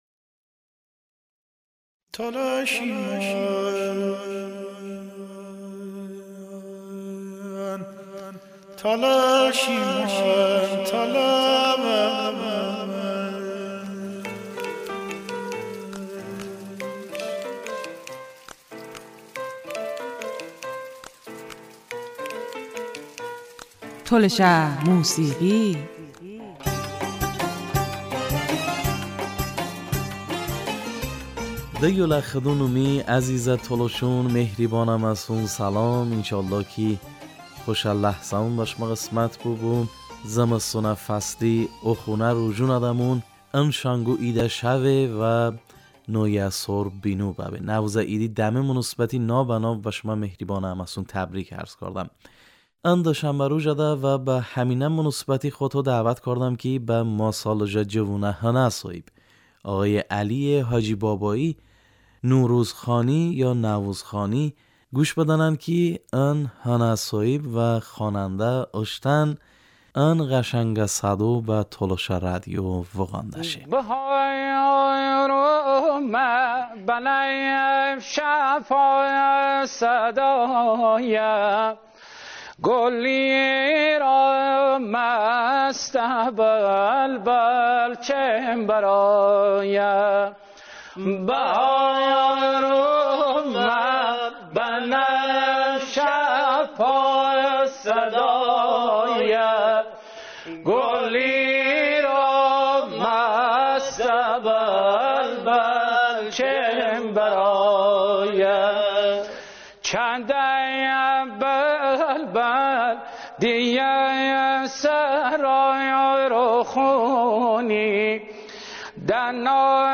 Tolışə musiği
Tolışə musiği(Masalıjə xanəndə